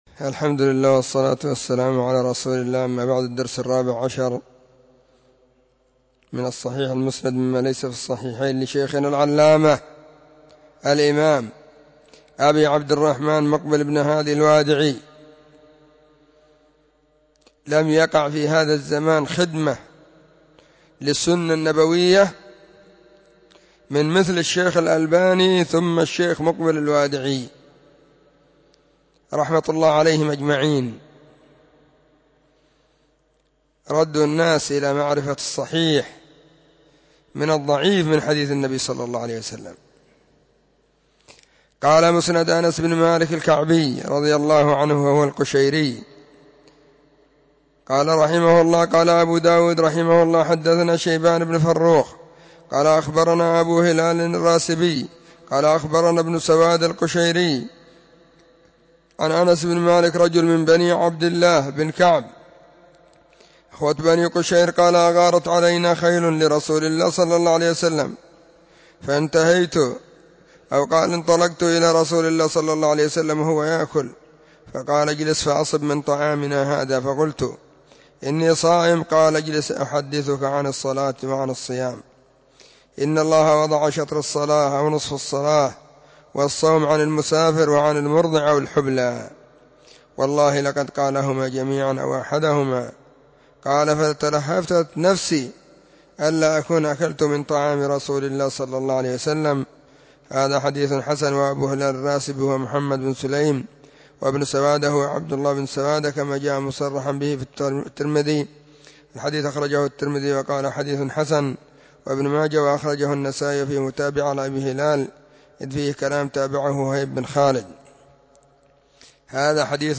📖 الصحيح المسند مما ليس في الصحيحين = الدرس: 14
خميس -} 📢مسجد الصحابة – بالغيضة – المهرة، اليمن حرسها الله.